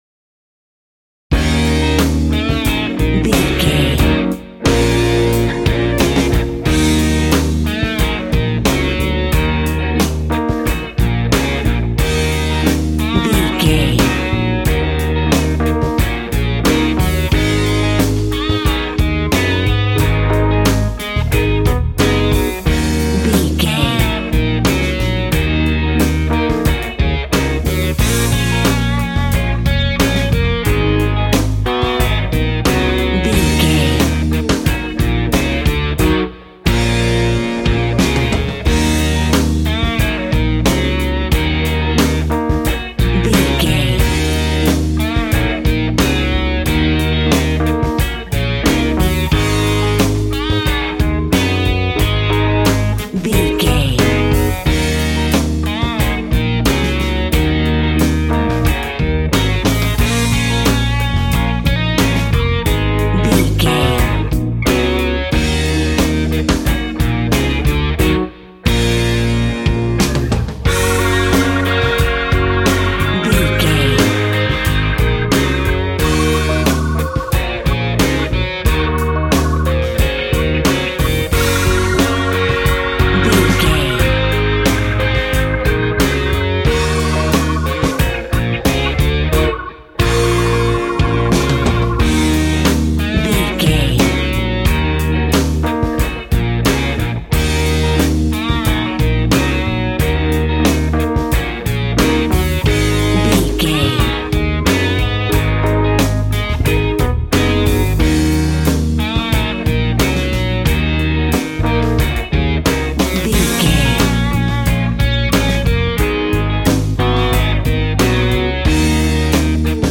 Aeolian/Minor
E♭
sad
mournful
bass guitar
electric guitar
electric organ
drums